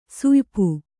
♪ suypu